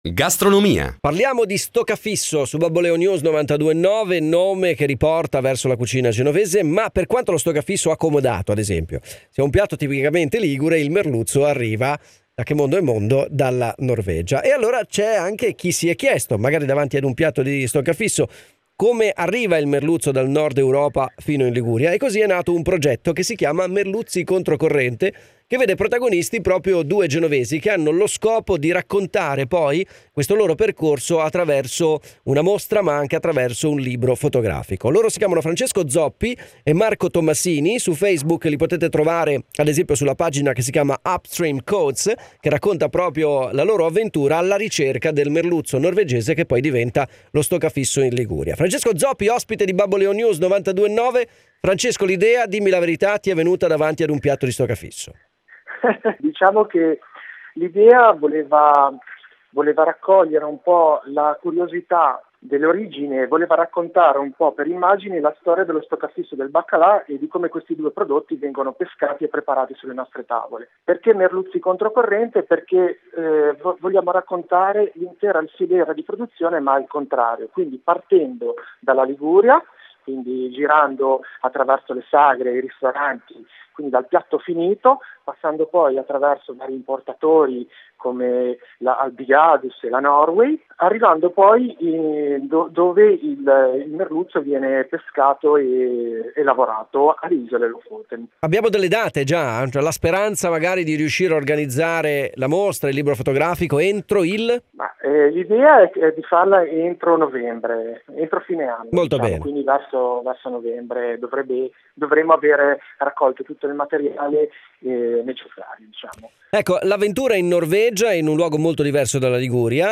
Interview at Radio Babboleo News for Upstream Cods